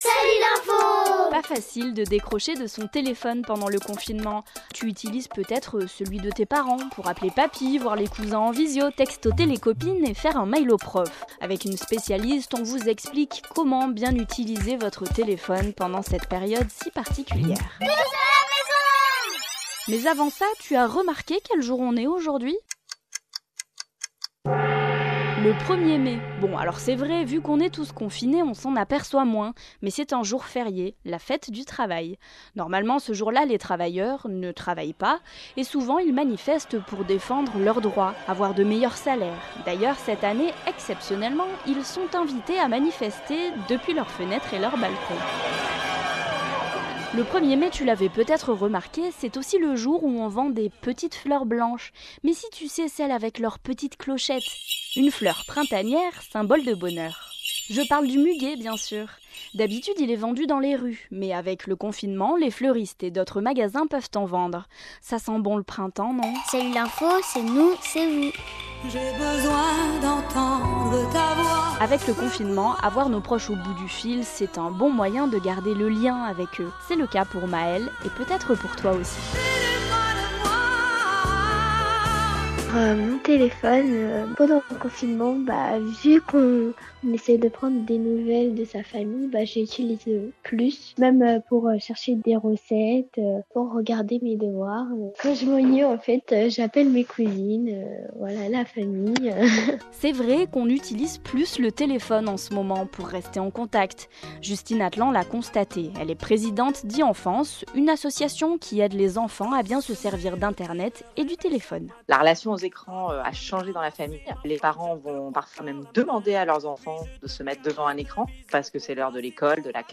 Pendant le confinement, l’émission quotidienne “Tous à la maison !” donne la parole aux enfants !